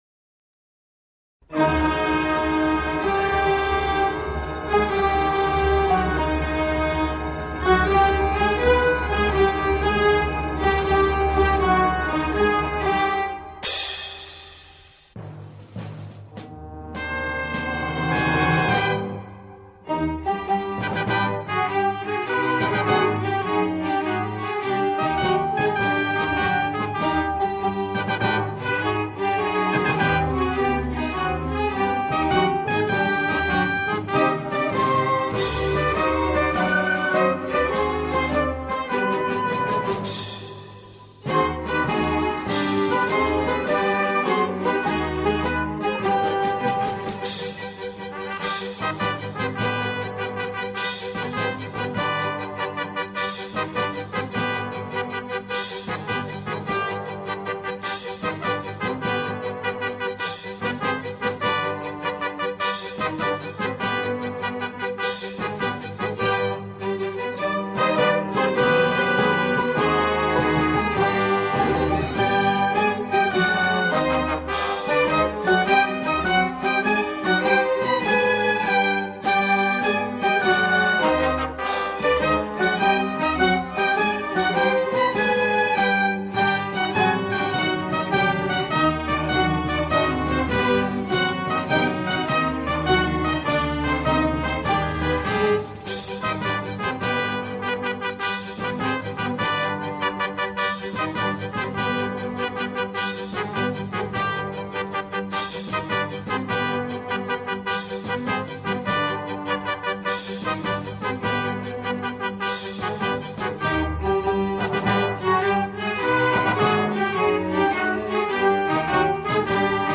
سرود قدیمی